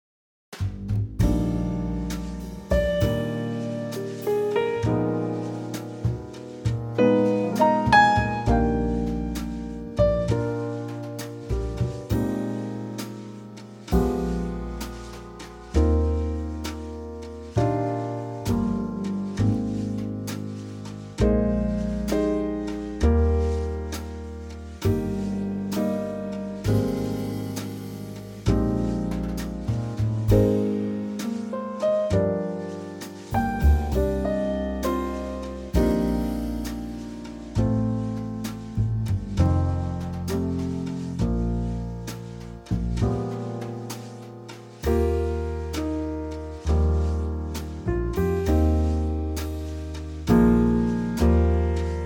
Unique Backing Tracks
key - Eb - vocal range - Eb to Eb
Gorgeous Trio arrangement
slowed down with oodles of lovely subtle musicality added.